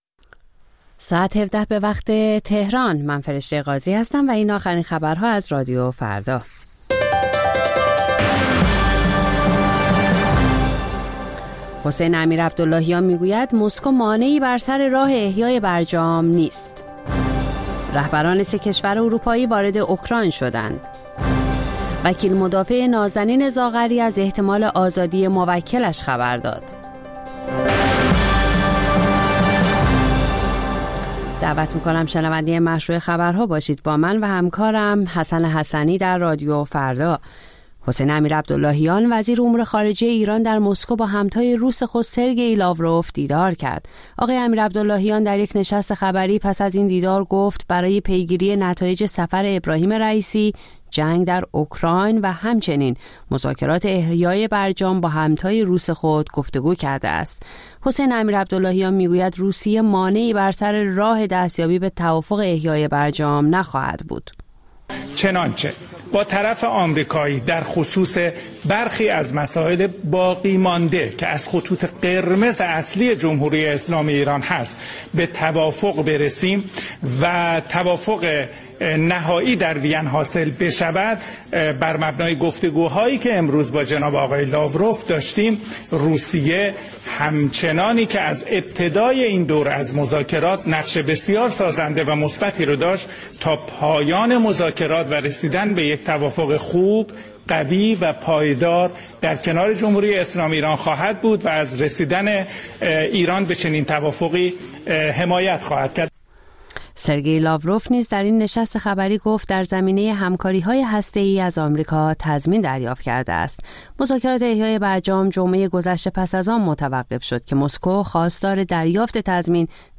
خبرها و گزارش‌ها ۱۷:۰۰